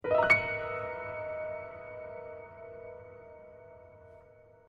Здесь собраны необычные звуковые композиции: от тонких природных мотивов до абстрактных эффектов, способных вызвать яркие ассоциации.
Звук догадливости на пианино